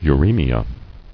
[u·rae·mi·a]